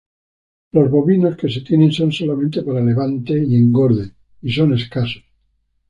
/enˈɡoɾde/